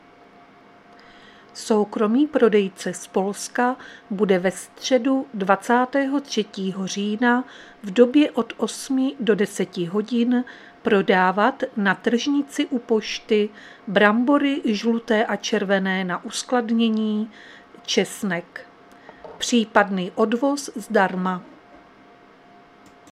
Záznam hlášení místního rozhlasu 22.10.2024
Zařazení: Rozhlas